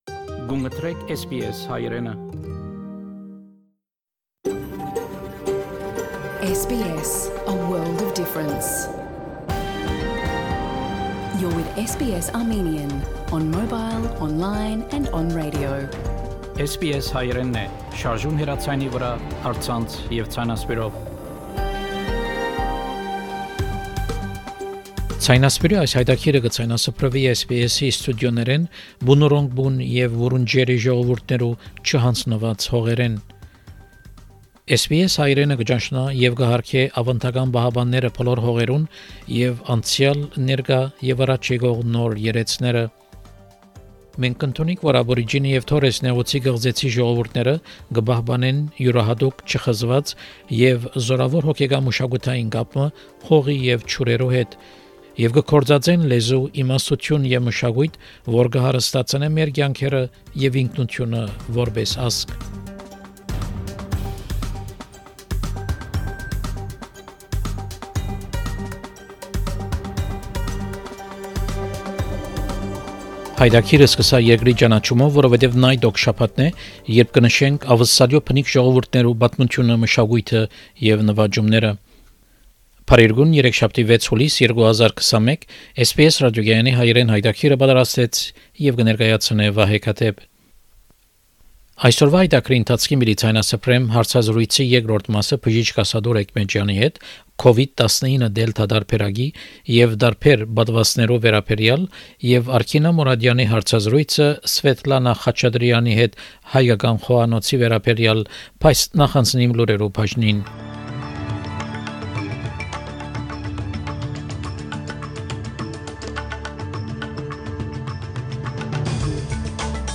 SBS Armenian news bulletin – 6 July 2021
SBS Armenian news bulletin from 6 July 2021 program.